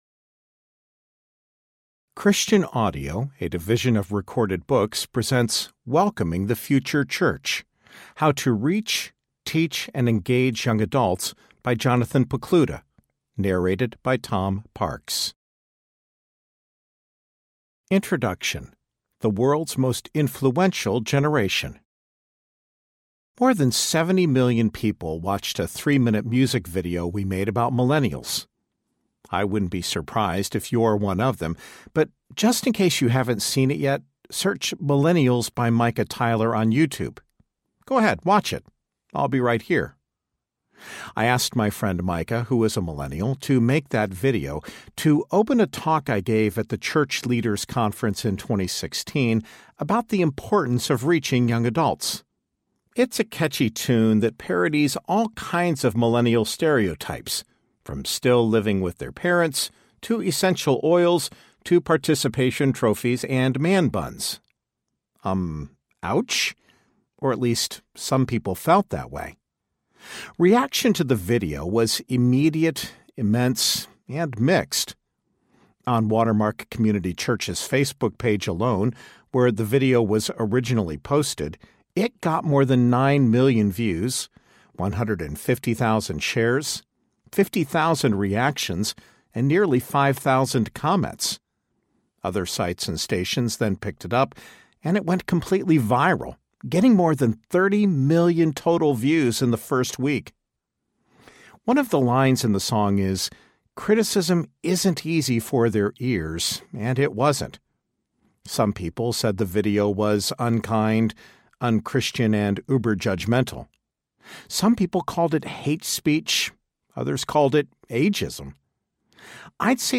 Welcoming the Future Church Audiobook
Narrator
4.7 Hrs. – Unabridged